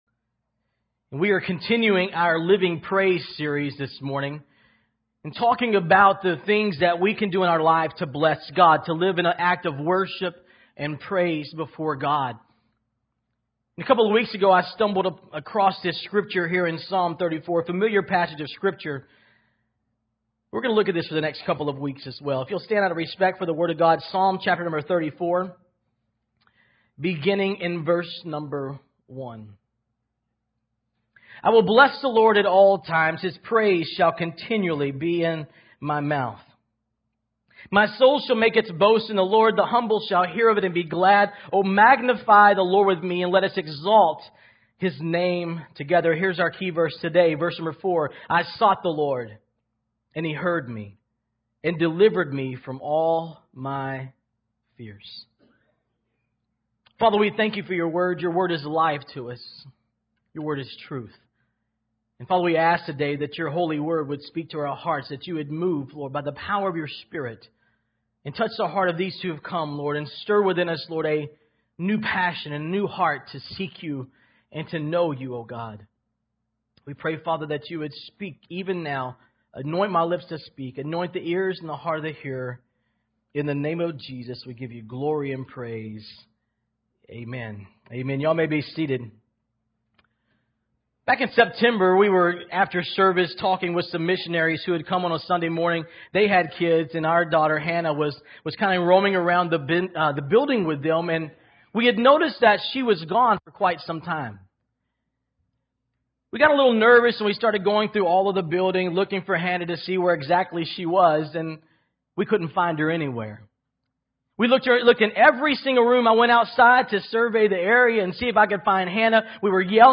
Here is Sunday’s message: LIVING PRAISE – Seeking God.